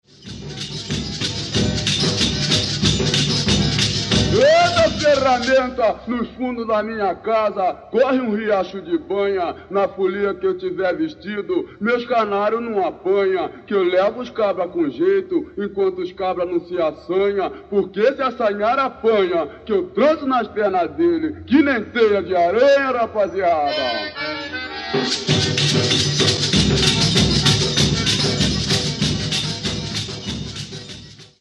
Chula (atividade musical)
Atividade musical de caráter festivo sendo formada por melodias alegres e vivas com letras maliciosas e buliçosas. É executada em ranchos e folias-de-reis.